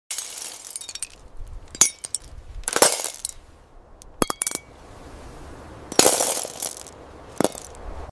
ASMR glass garden fruit, blueberry